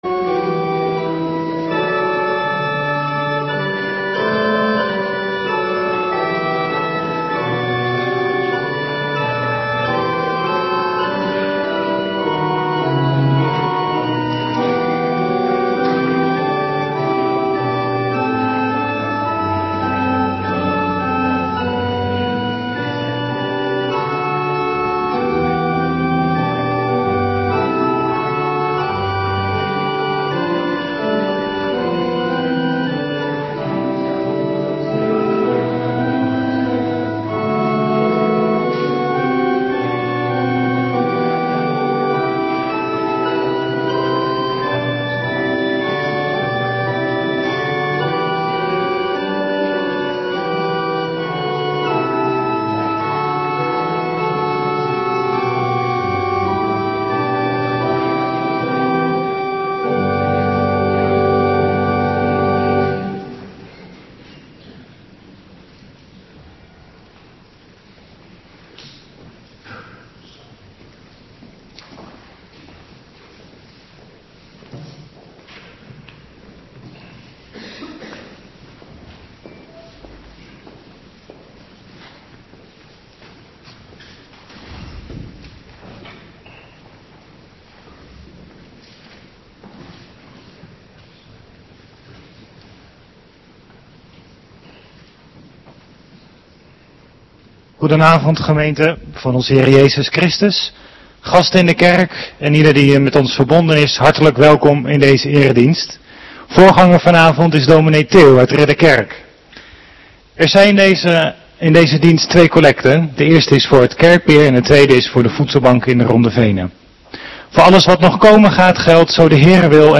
Avonddienst 4 januari 2026